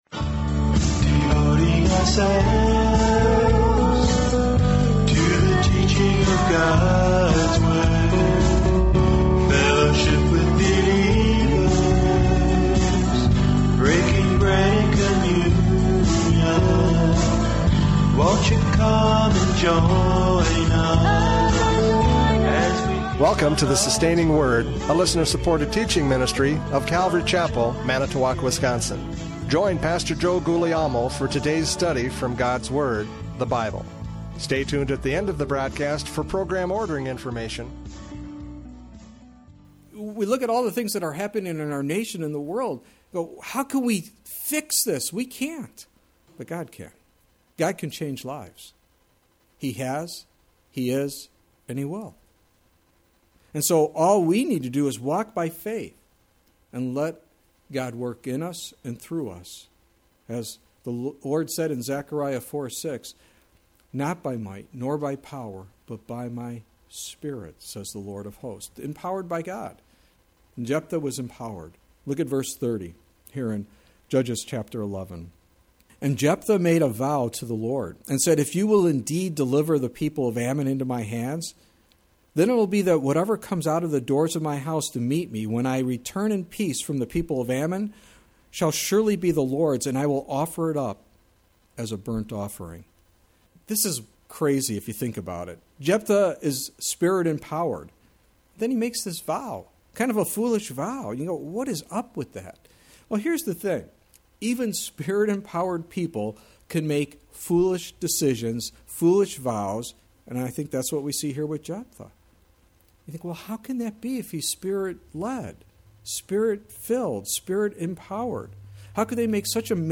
Judges 11:29-40 Service Type: Radio Programs « Judges 11:29-40 A Foolish Vow!